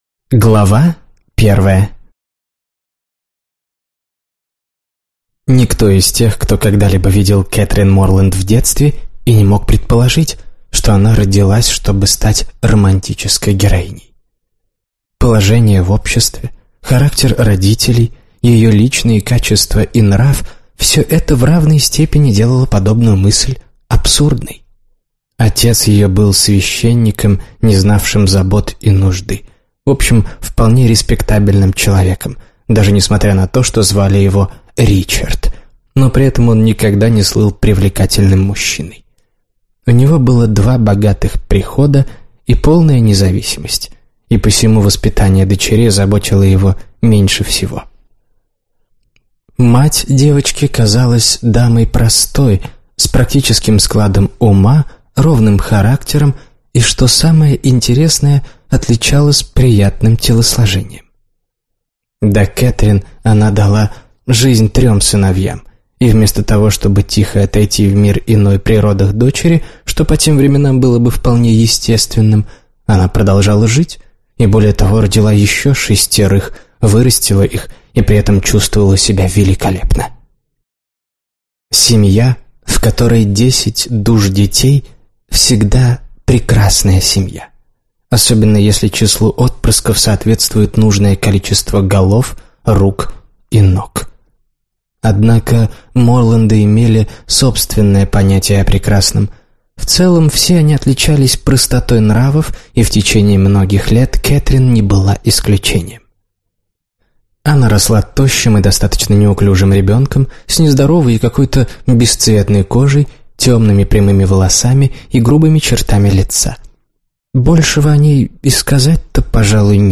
Аудиокнига Нортенгерское аббатство - купить, скачать и слушать онлайн | КнигоПоиск